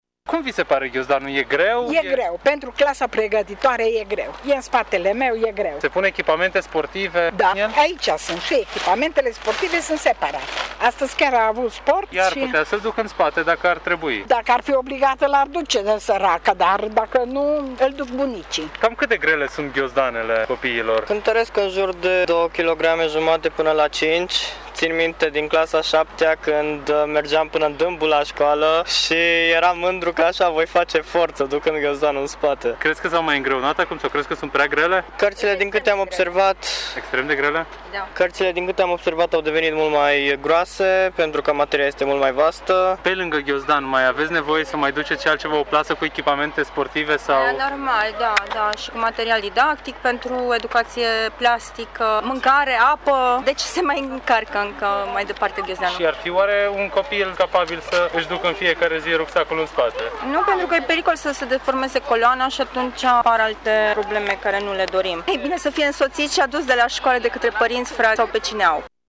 Pe lângă ghiozdane, elevii mai au nevoie și de echipament sportiv care, de multe ori este transportat separat dar și unelte specifice artelor plastice, spun părinții și elevii mureșeni: